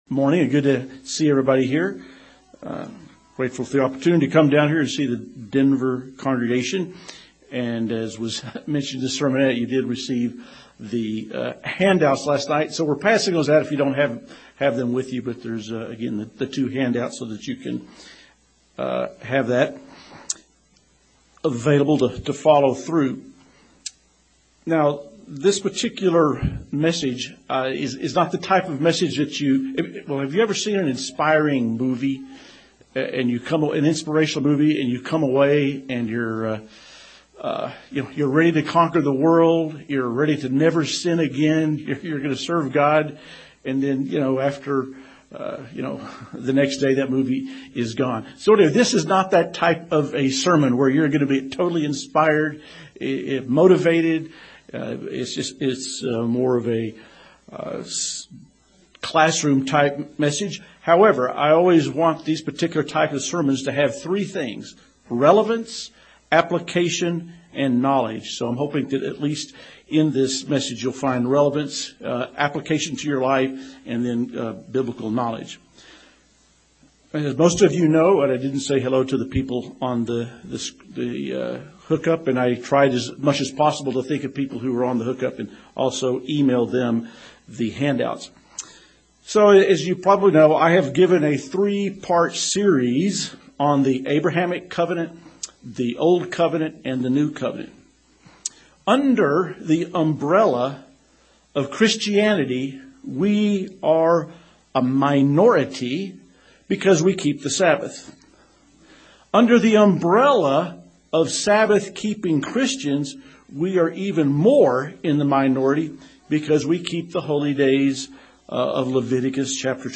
Sermons
Given in Denver, CO Loveland, CO